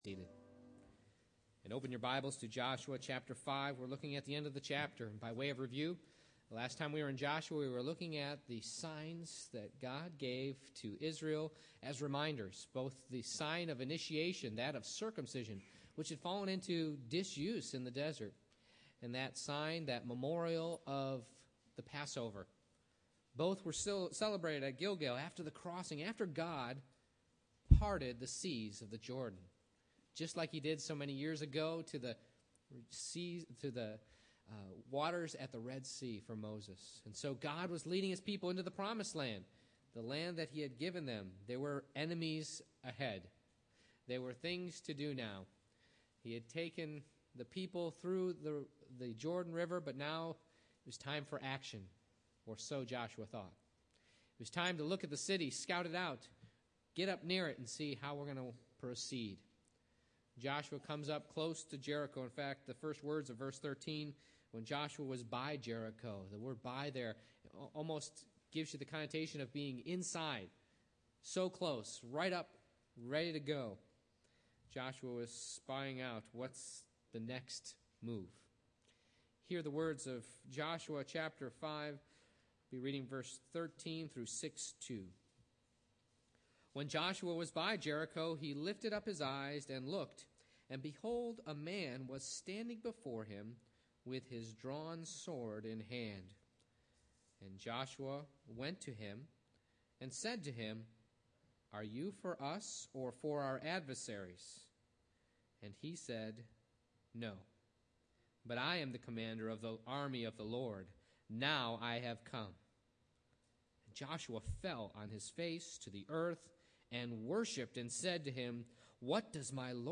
Joshua 5:13 Service Type: Morning Worship I. Who is this?